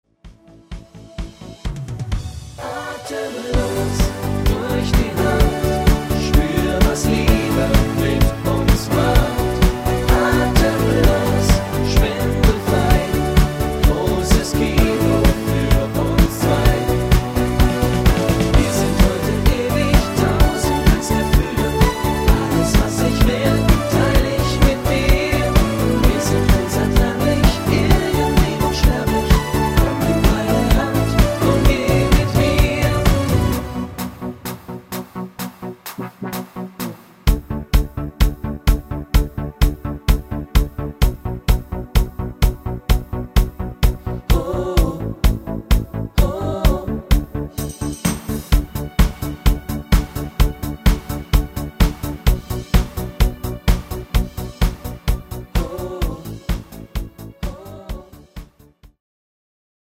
Stimmlage für Männer